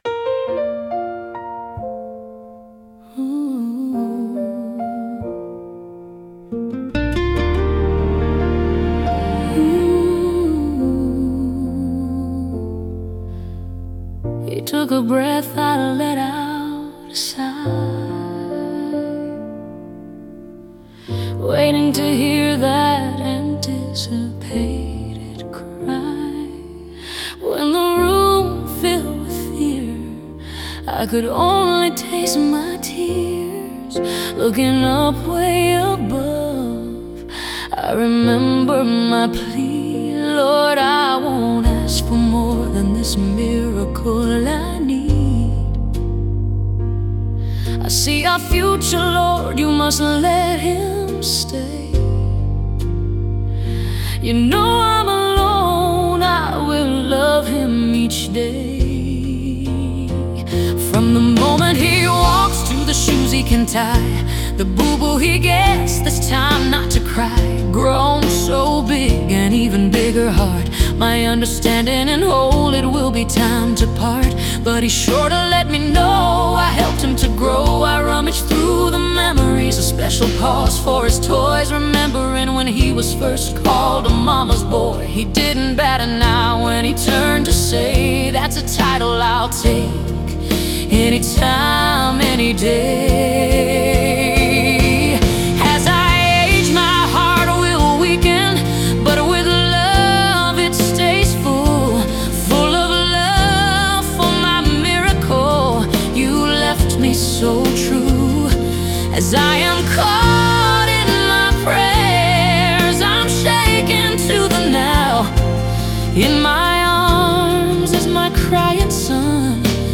Category: Country